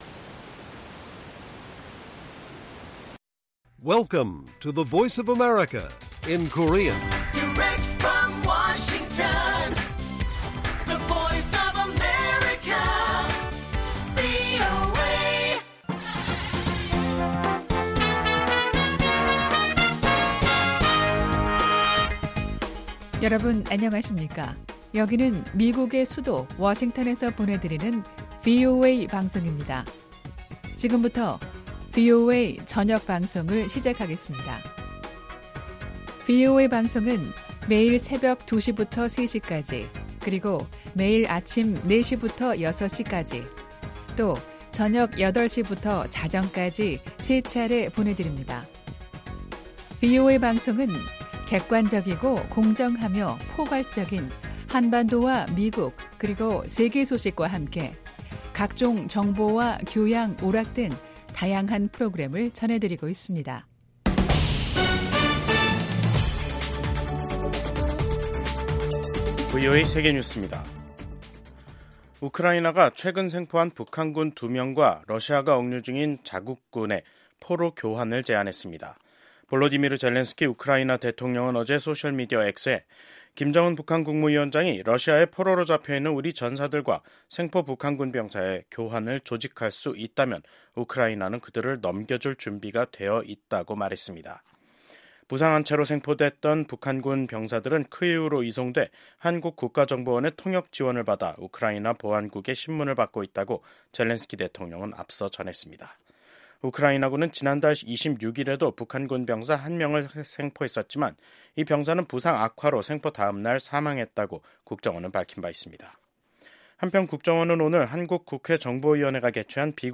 VOA 한국어 간판 뉴스 프로그램 '뉴스 투데이', 2025년 1월 13일 1부 방송입니다. 러시아 서부 쿠르스크 지역에서 20살과 26살인 북한 군인 2명이 생포됐습니다.